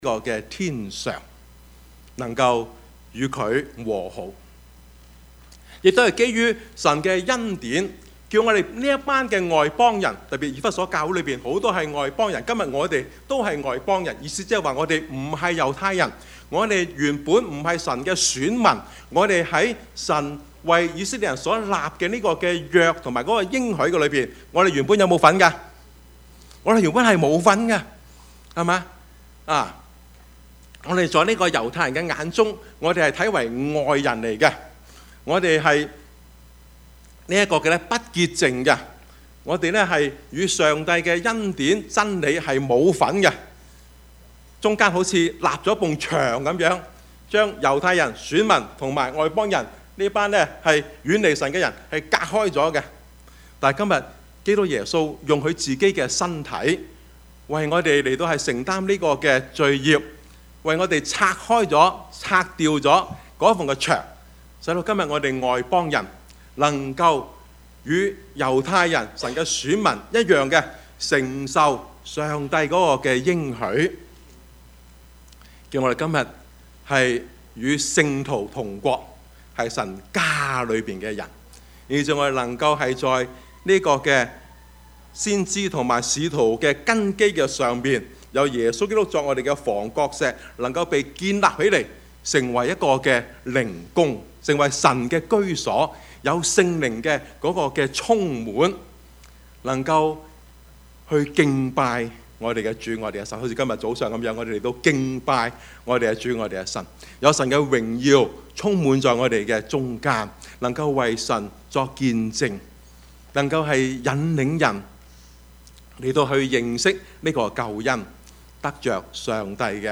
Service Type: 主日崇拜
Topics: 主日證道 « 婚姻與兒女 新人舊人 »